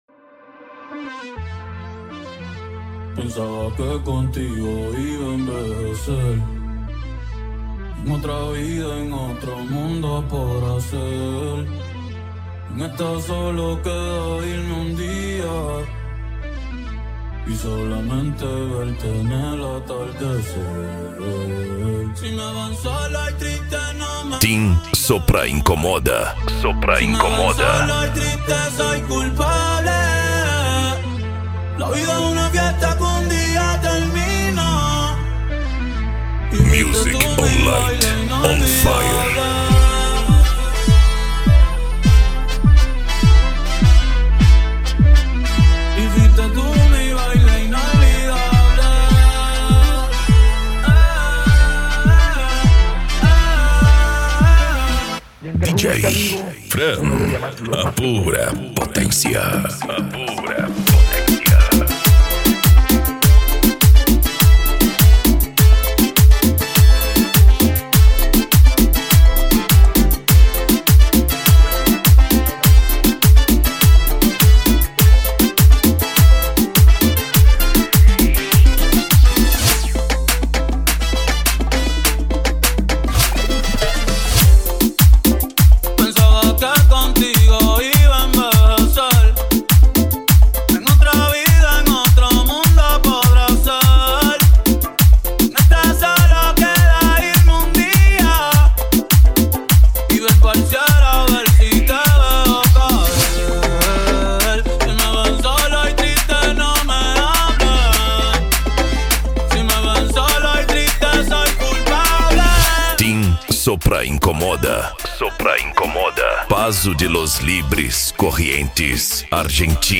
Remix
Funk
Bass